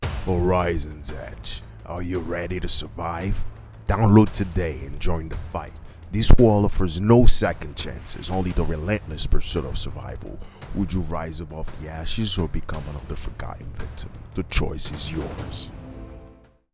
Videogame Online Ad: Gritty, determined, survival, suspenseful atmospheric and post-apocalyptic